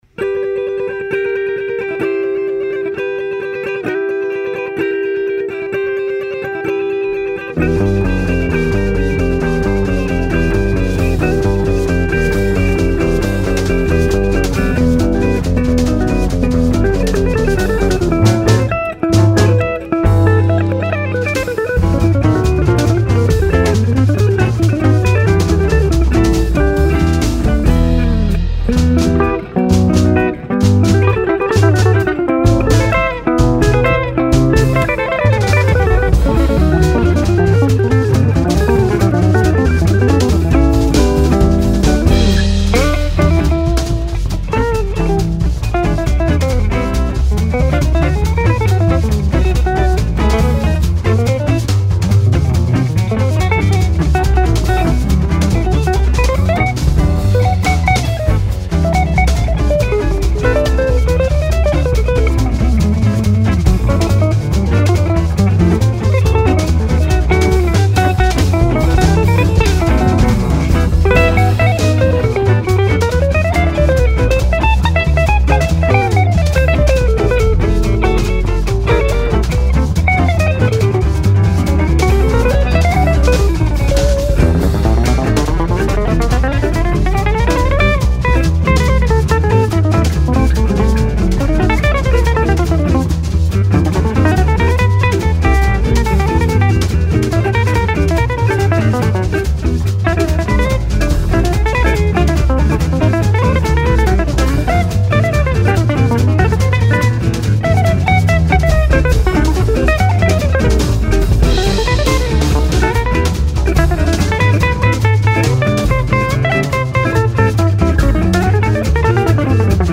guitares
contrebasse
batterie, chant